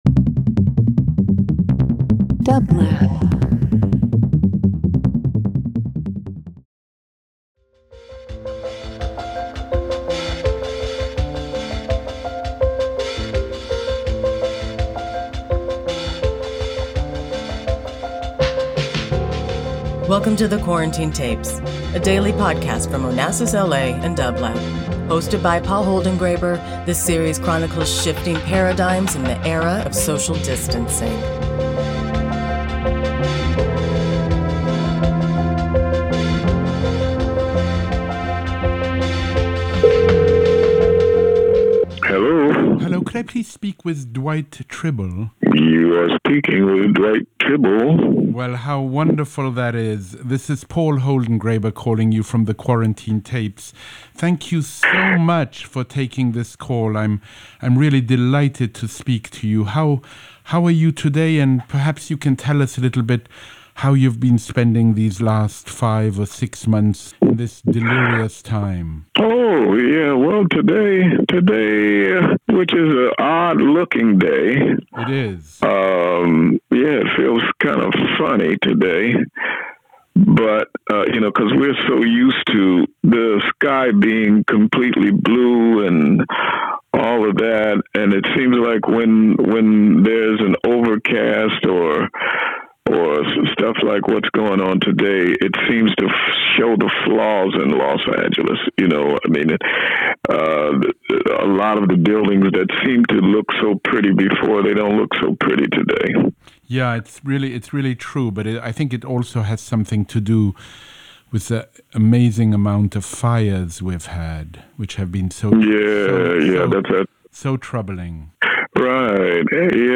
Each day, Paul calls a guest for a brief discussion about how they are experiencing the global pandemic.
About episode 109: On episode 109 of The Quarantine Tapes, Paul Holdengräber is joined by musician Dwight Trible. Dwight tells Paul about how quarantine has allowed him to establish a routine.